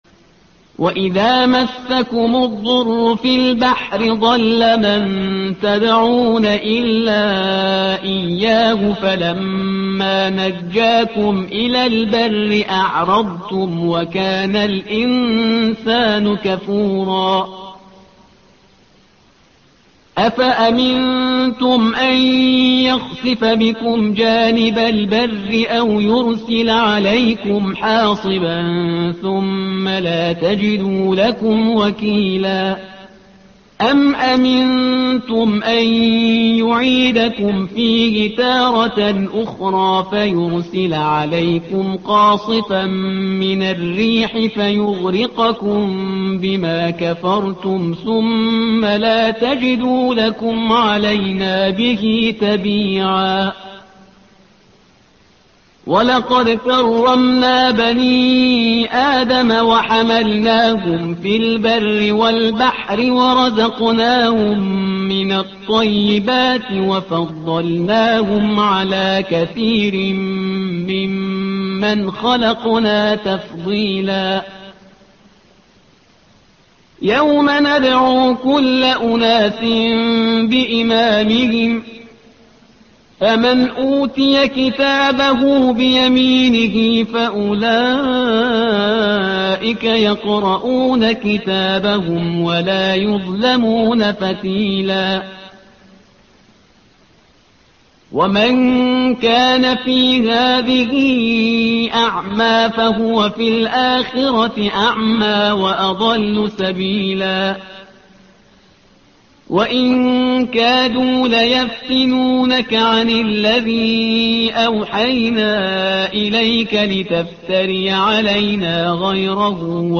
الصفحة رقم 289 / القارئ